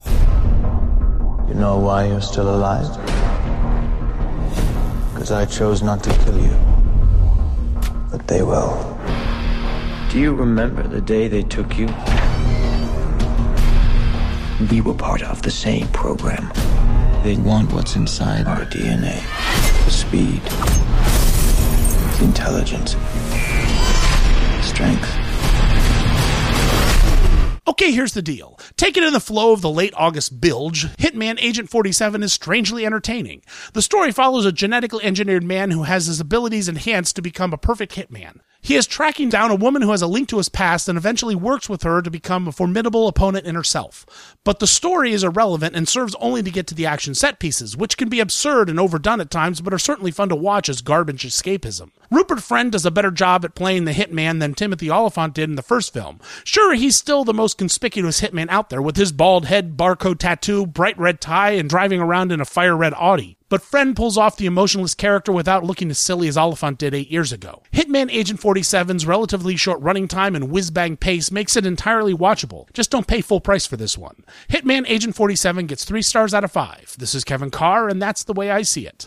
‘Hitman: Agent 47’ Movie Review